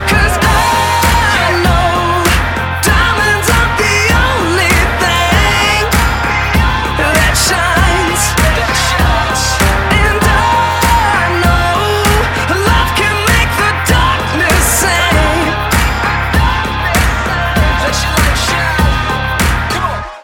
• Качество: 192, Stereo
Красивый рэпкор